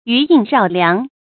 余音绕梁 yú yīn rào liáng
余音绕梁发音
成语正音绕，不能读作“rǎo”。